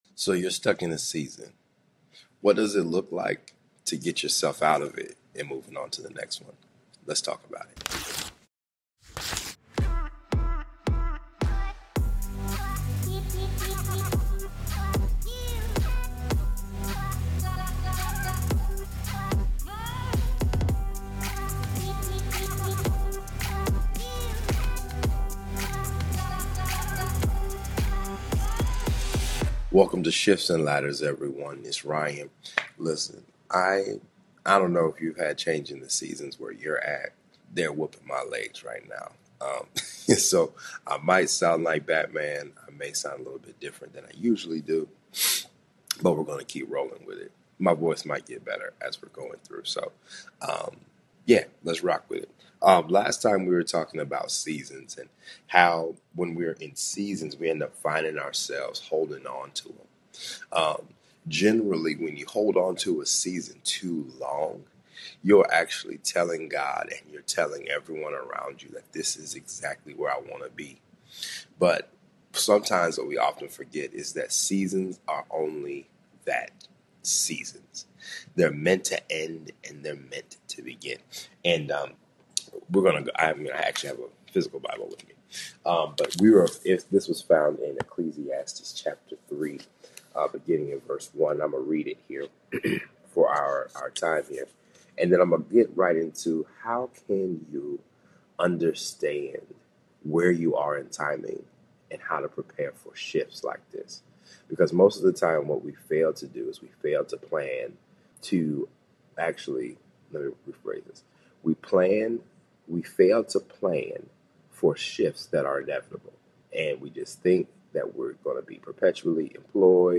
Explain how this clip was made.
**Note: I apologize for the audio quality here, the mac had a little oopsies and selected the wrong microphone.